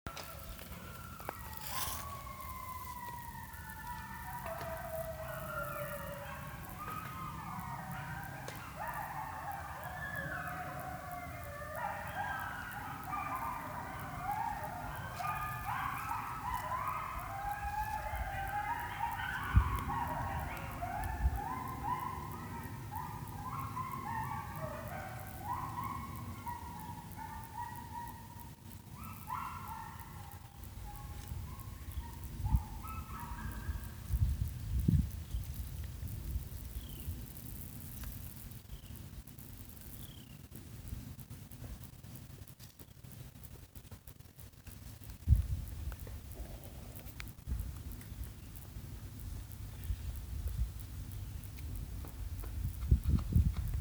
Coyote pack howling in a Tennessee mountain cove.
A pack reunion on a warm afternoon. The coyotes are calling from a jumble of sandstone boulders on a south-facing slope near Sewanee: